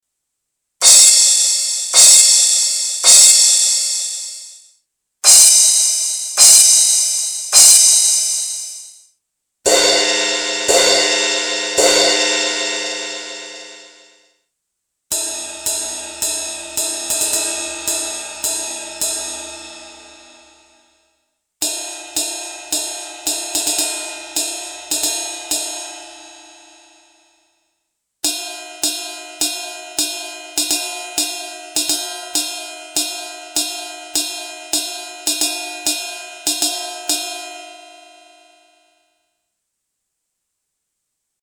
• Cymbal Combo (3 crashes and 3 positions of ride)
Remember: Since we are EXCESSIVELY boosting everything, naturally all characteristics will come through with way too much “spice”… but you WILL hear that spice clearly!
4 (8kHz)
fk6_Cymbal_Combo_8k_boost.mp3